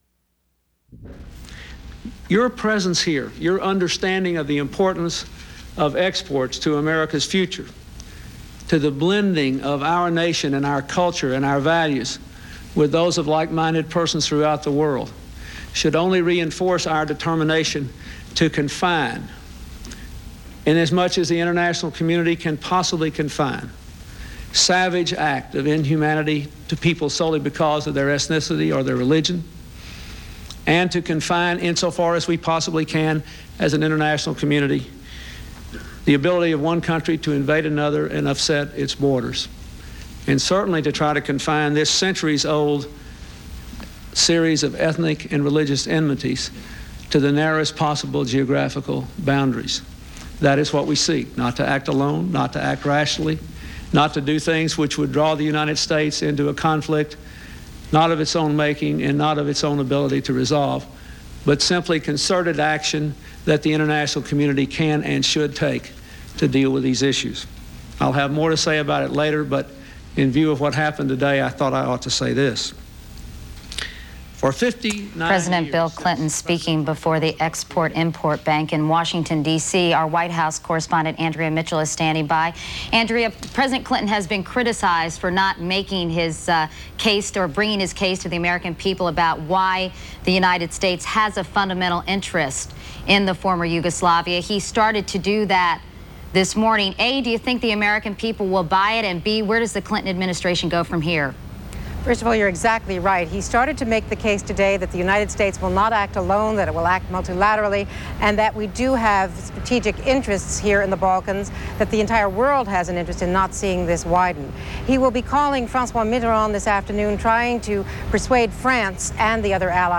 U.S. President Bill Clinton reacts to the Bosnia-Serb rejection of the peace agreement in the former Yugoslavia